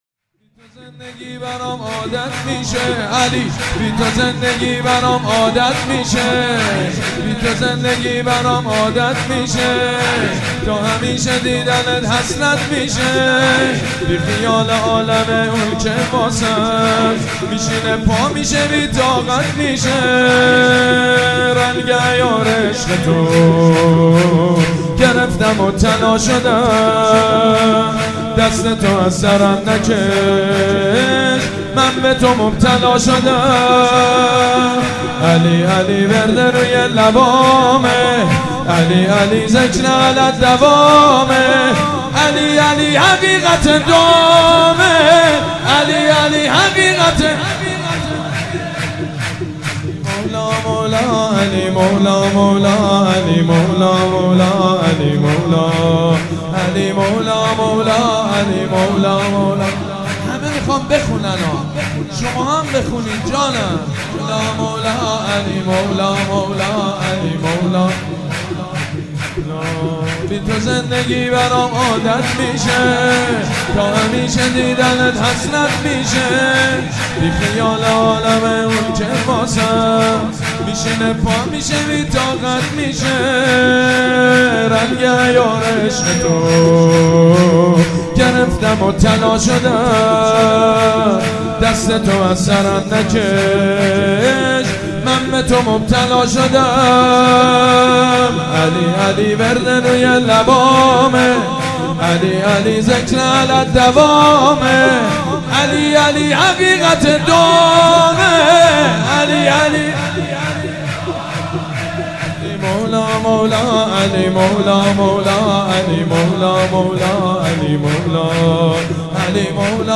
مولودی های مناسب عید مبعث با صدای مداحان مشهور